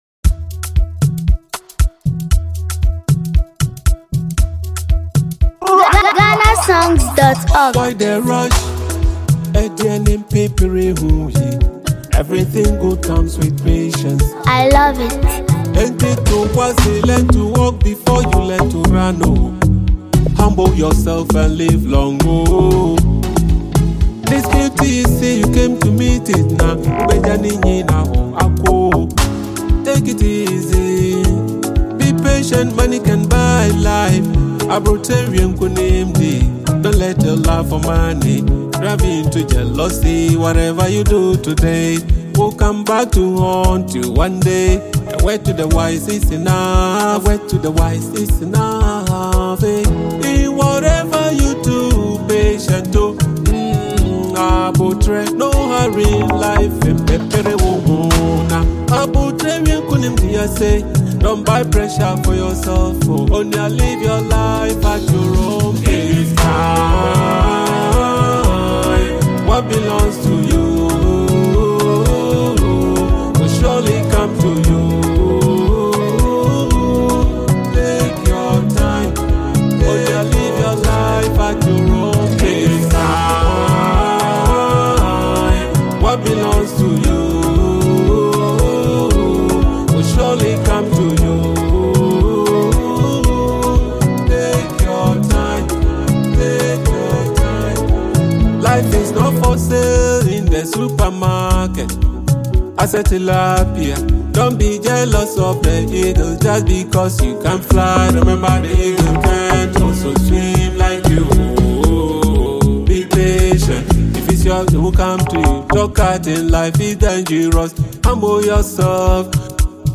smooth Afrobeat vibes
featuring soft instrumentals and a mid-tempo Afrobeat rhythm